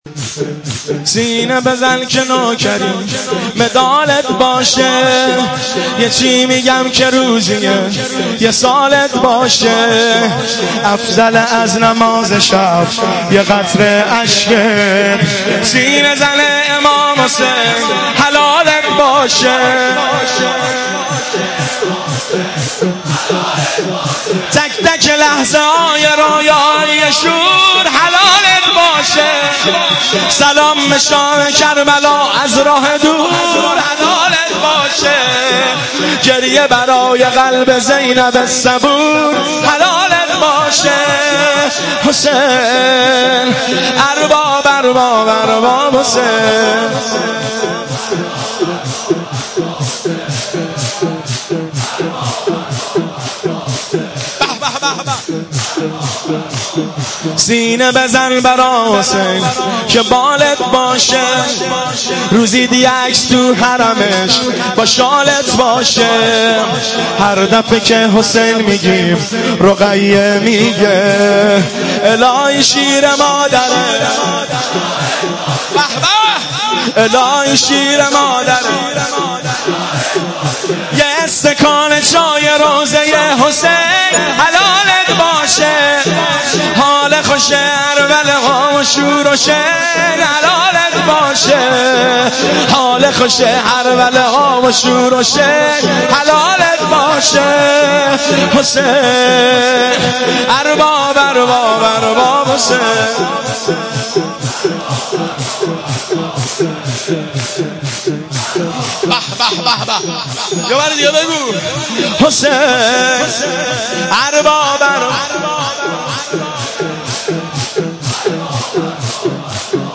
شب سوم محرم